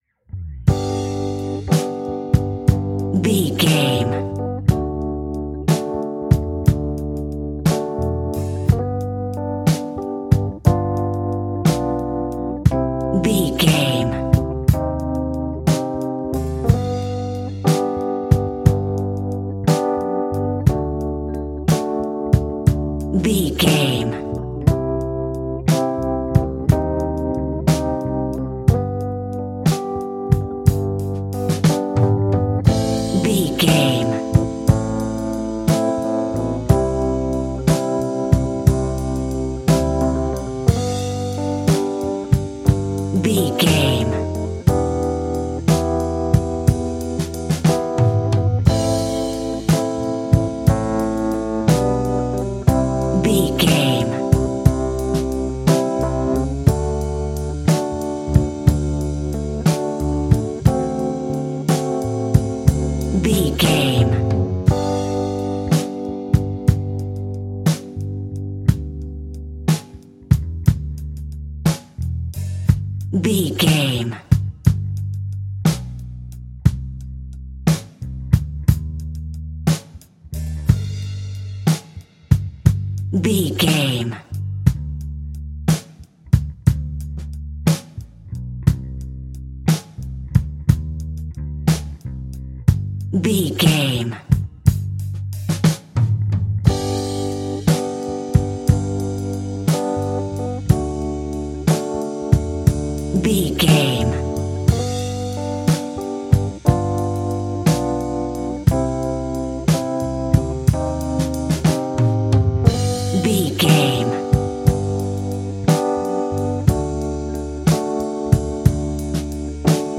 Aeolian/Minor
funky
uplifting
bass guitar
electric guitar
organ
saxophone